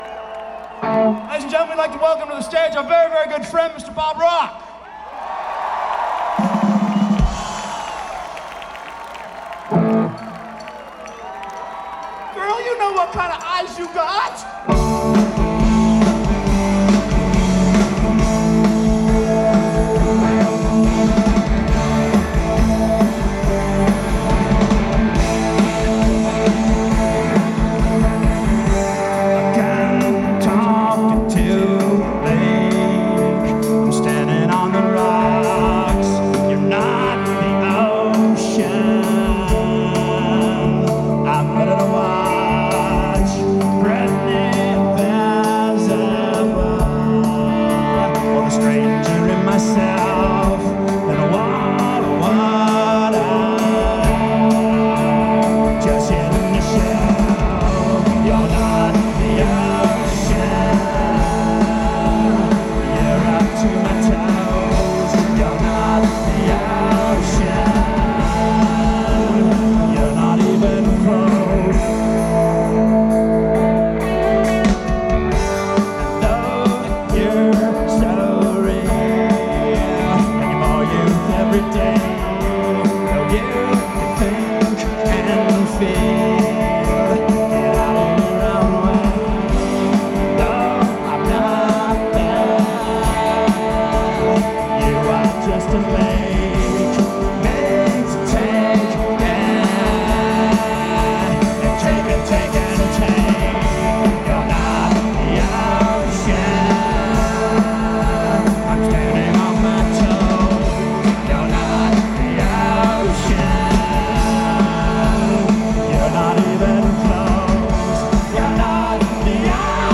Source: Audience